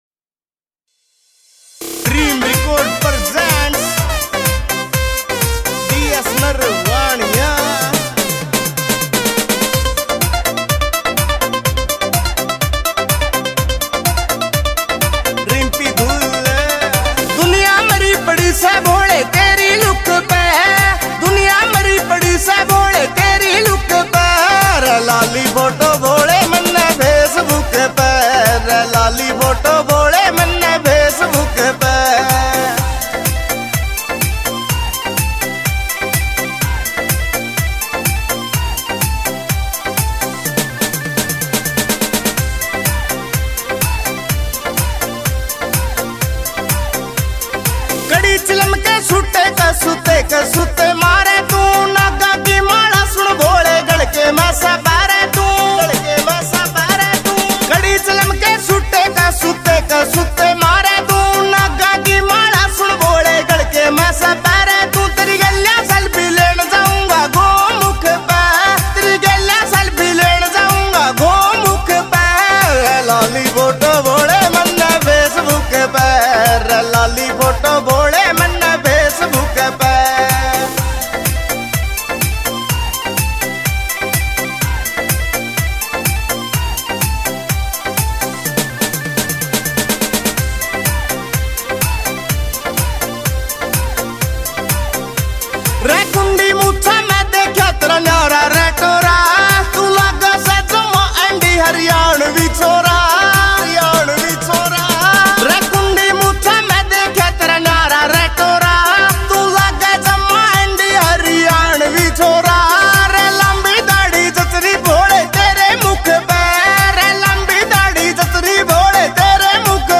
» Bhakti Songs